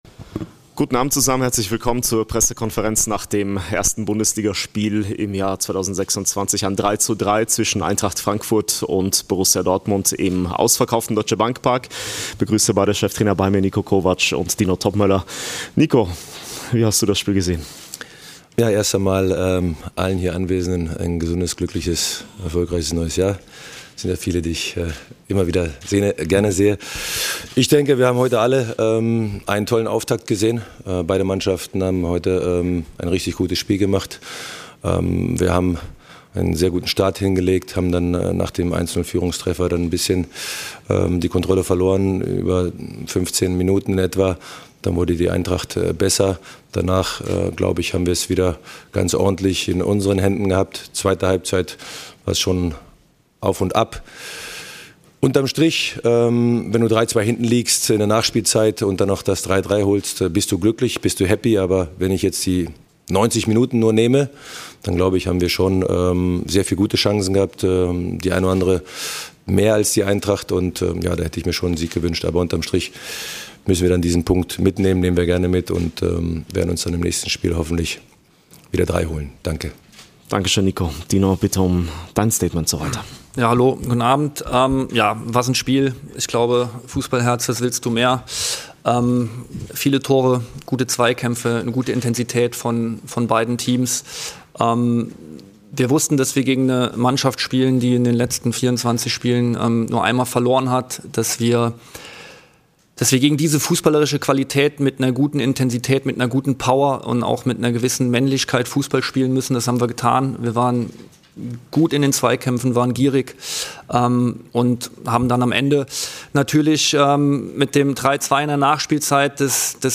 Die Pressekonferenz mit den beiden Cheftrainern Niko Kovac und Dino Toppmöller nach packenden 90 Minuten im Deutsche Bank Park.